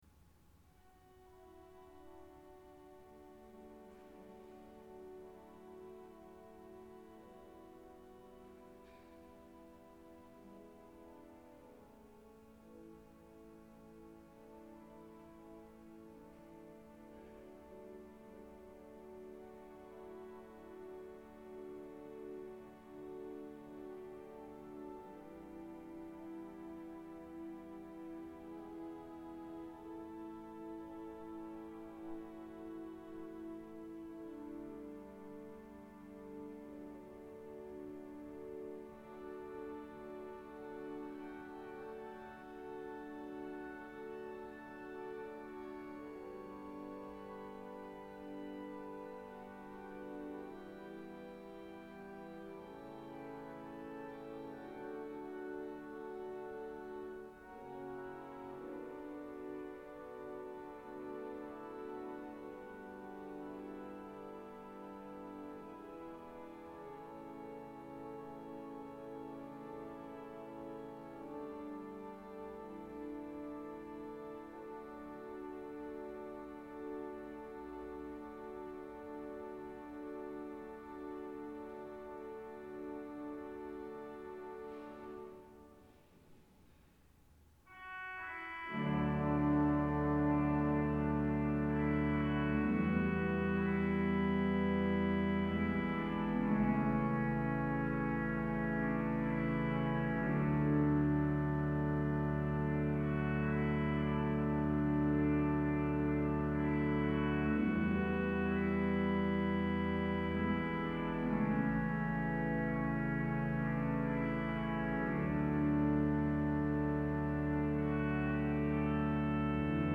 Margaretakyrkan i Oslo, Svenska kyrkan i Norge.
Orgelet har en veldig flott og syngende klang.
To flotte instrumenter plassert i et vakkert og høyreist rom.
Margaretakyrkan   ZOOM H4n PRO 18.10.2019